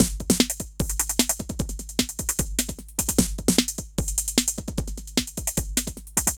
CRATE C DRUM 1.wav